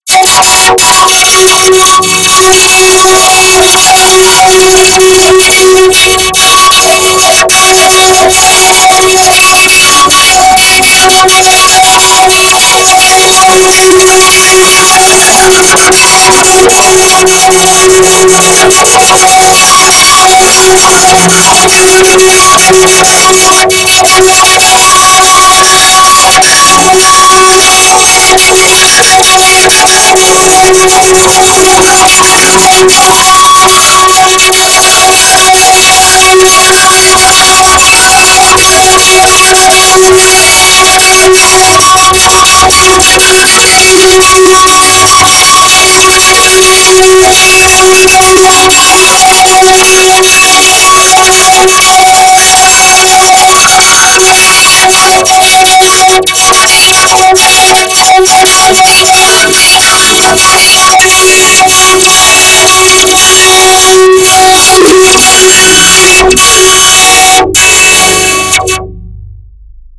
How about 'o vocoded to 'g?